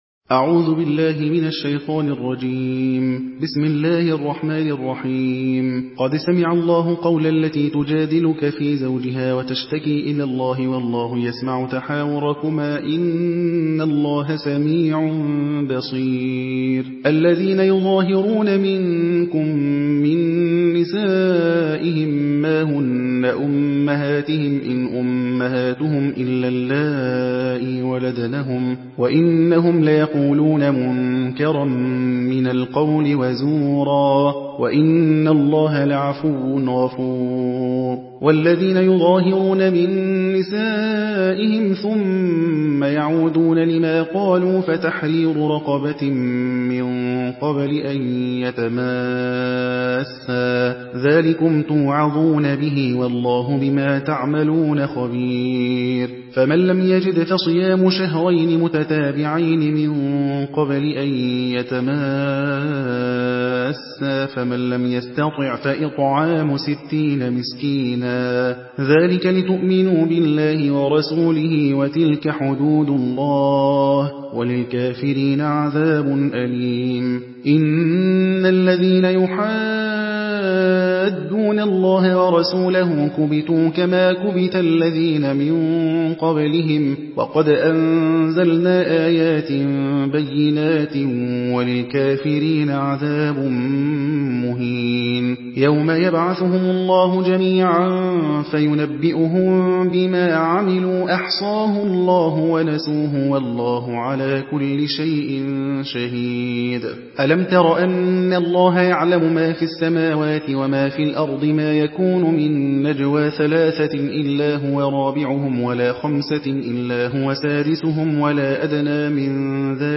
حدر